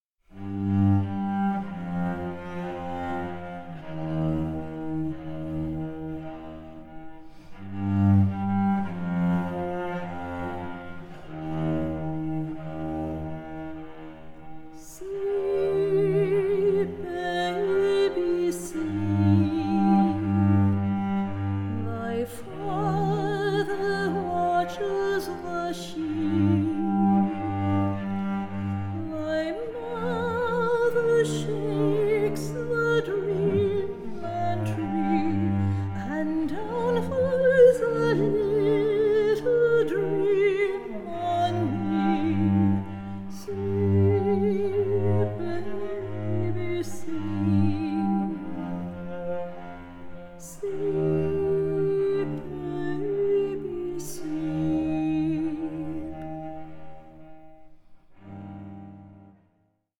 HAUNTING, GENTLE SPIRITS, DREAMS, AND LULLABIES
all above a rich bed of cellos